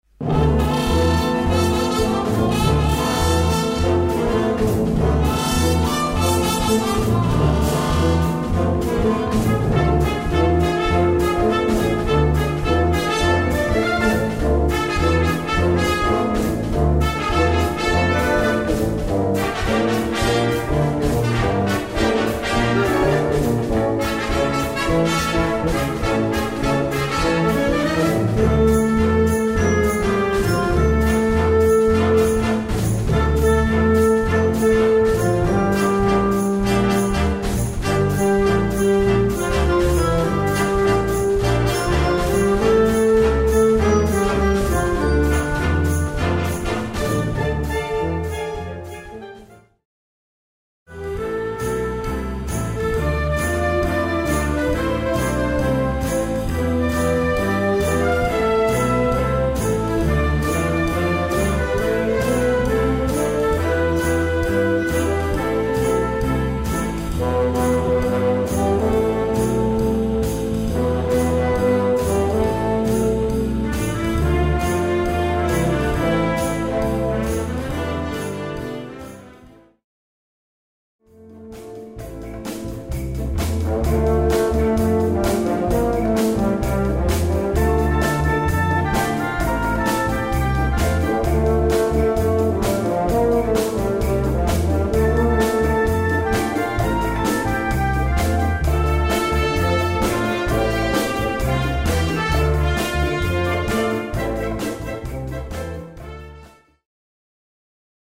8:30 Minuten Besetzung: Blasorchester Zu hören auf